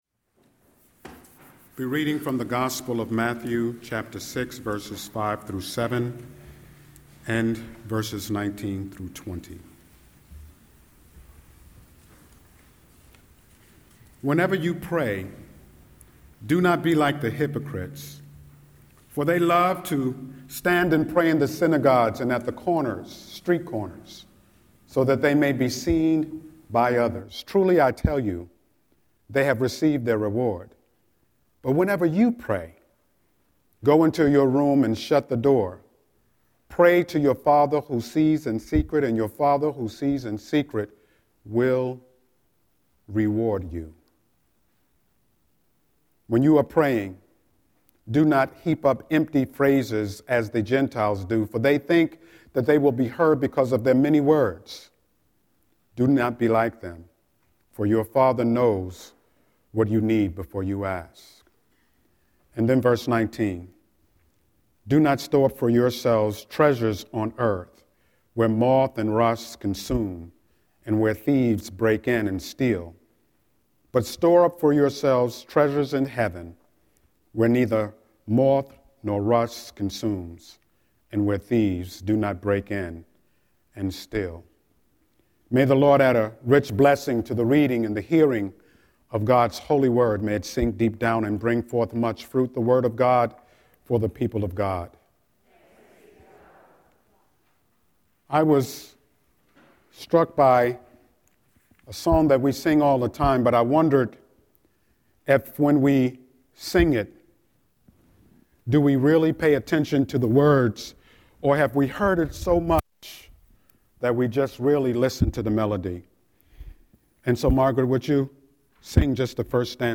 09-13-Scripture-and-Sermon.mp3